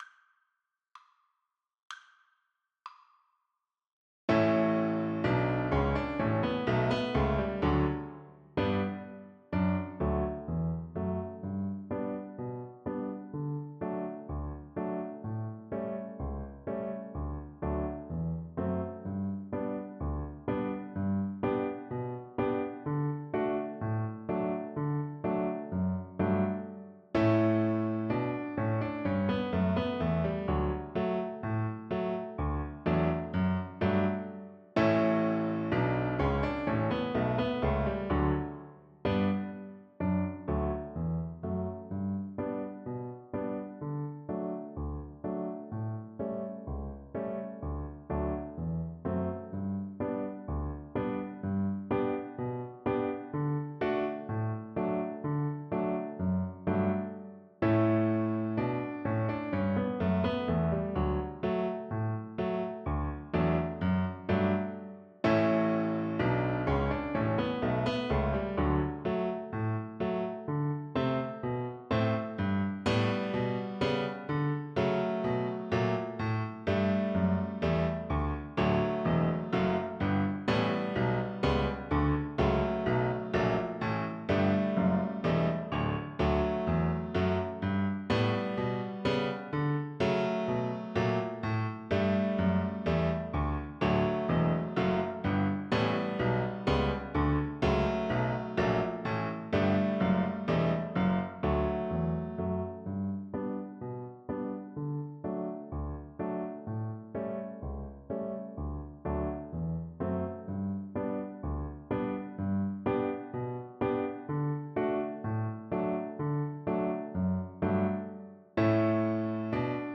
Traditional Trad. Kipera Cello version
Cello
D major (Sounding Pitch) (View more D major Music for Cello )
2/4 (View more 2/4 Music)
Traditional (View more Traditional Cello Music)
Finnish